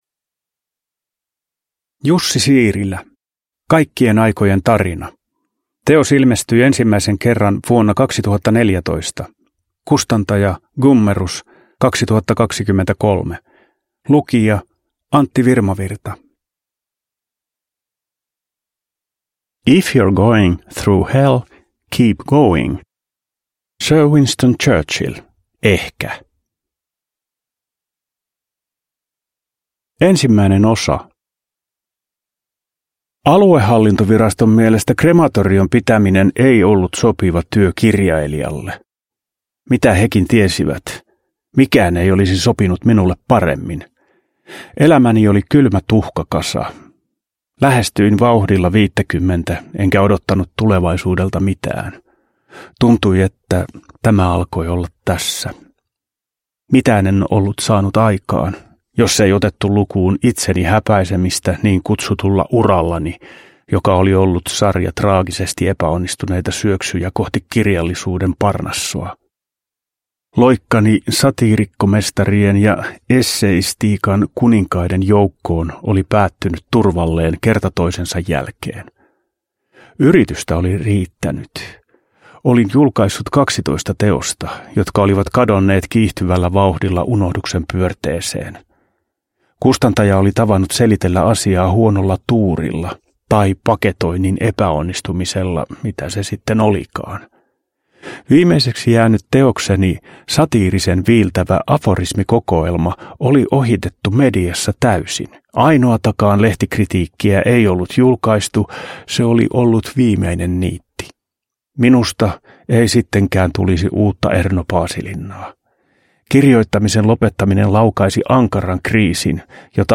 Kaikkien aikojen tarina – Ljudbok – Laddas ner